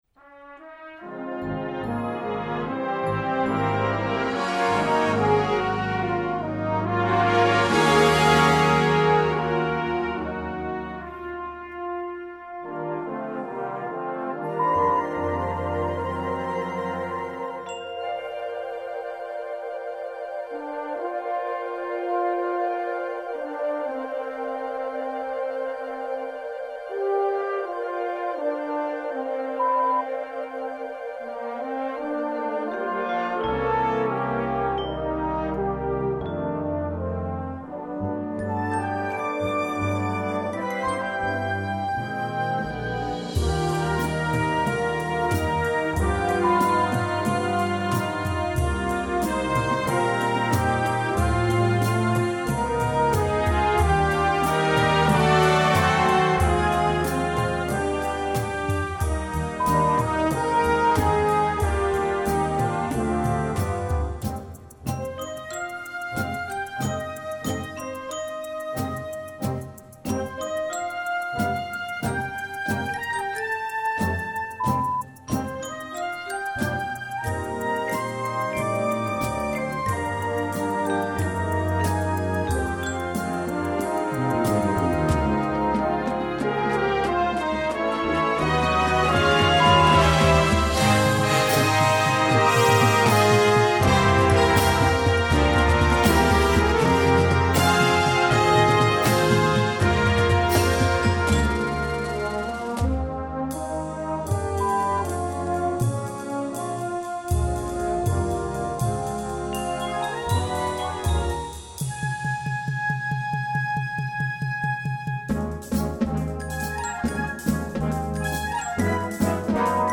Gattung: Moderner Einzeltitel
C Besetzung: Blasorchester Tonprobe